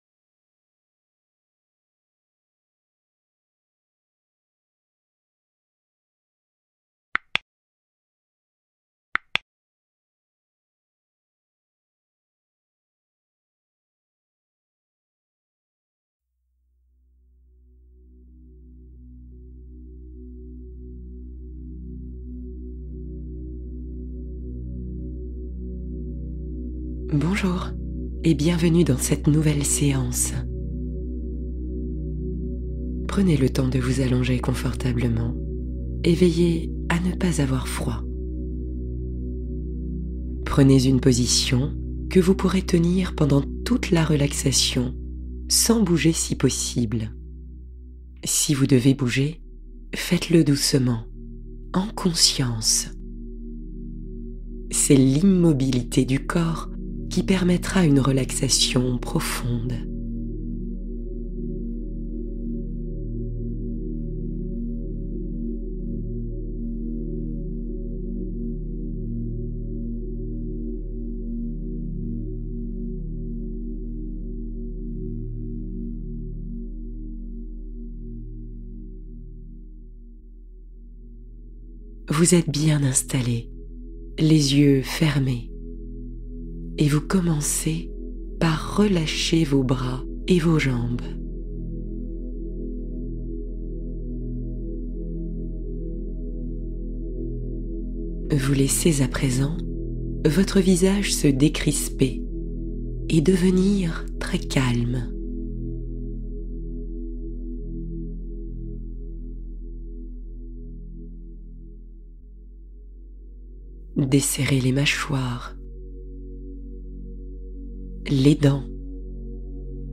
Yoga nidra — Douceur, lenteur et légèreté